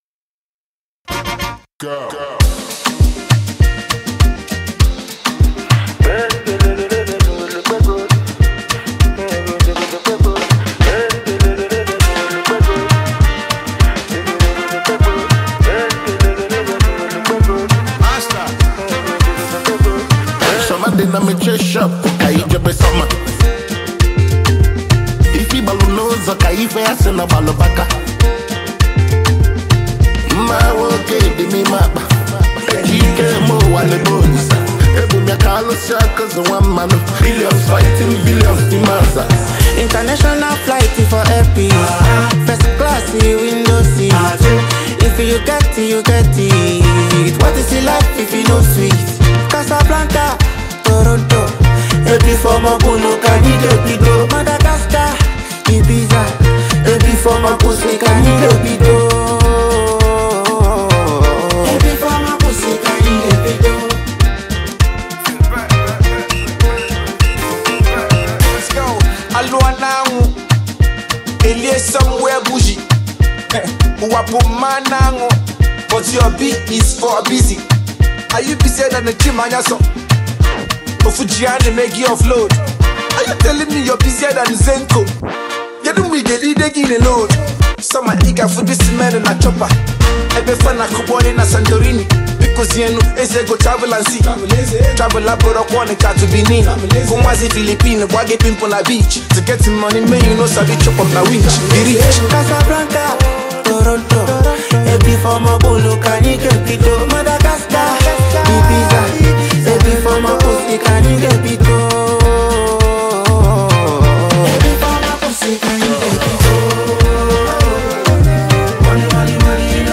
This energetic tune is a must-have on your playlist.